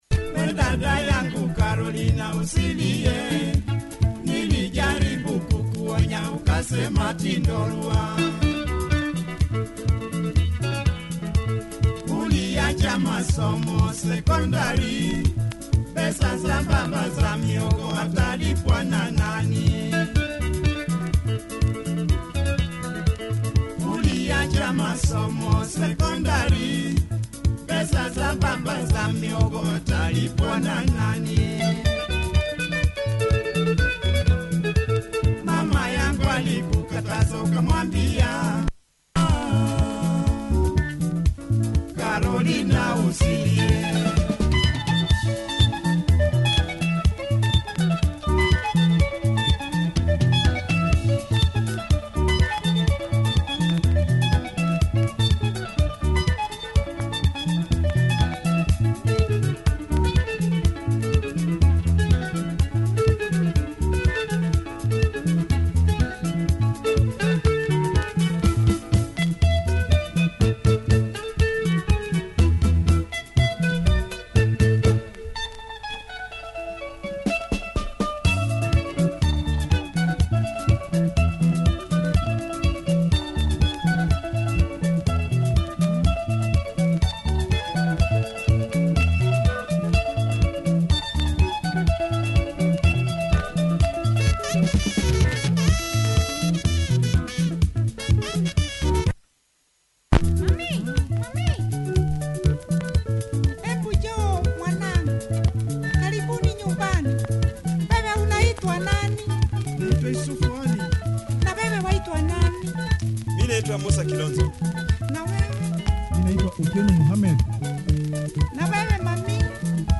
Swahili pop
nice sax and some other interesting instrumentation